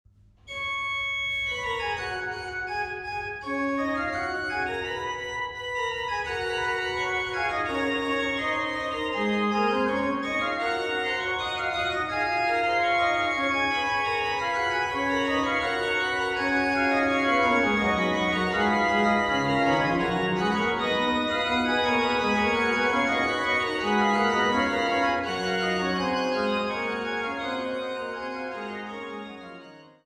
Die Orgeln im St. Petri Dom zu Bremen
Orgel